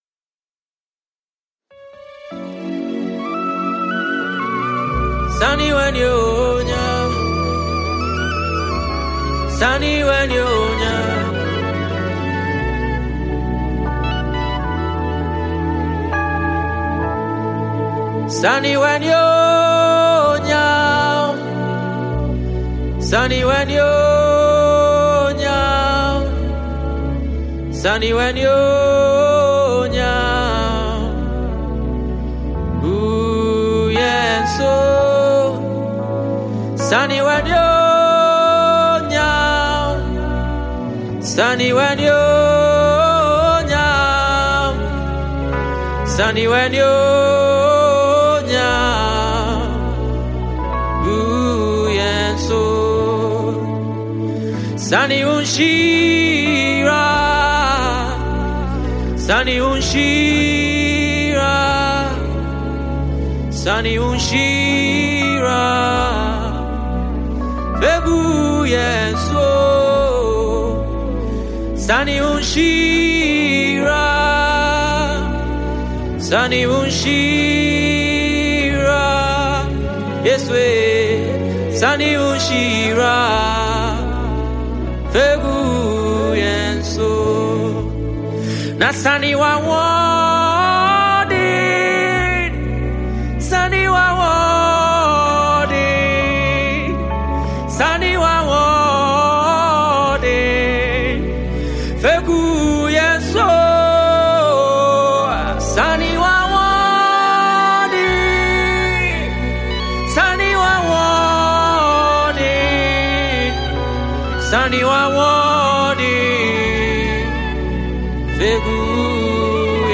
Ghanaian gospel act
worship song